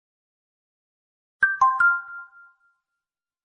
Category Message